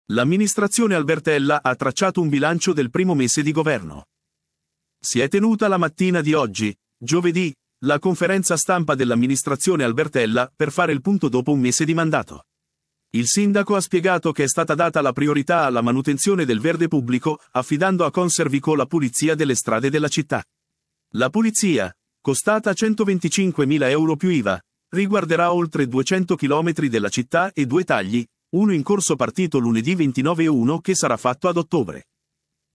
Si è tenuta la mattina di oggi, giovedì, la conferenza stampa dell’amministrazione Albertella per fare il punto dopo un mese di mandato. Il sindaco ha spiegato che è stata data la priorità alla manutenzione del verde pubblico affidando a ConserVco la pulizia delle strade della città.